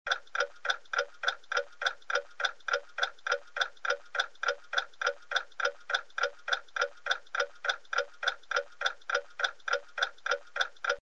Звуки тиканья
Тиканье часов — Тик-так, тик-так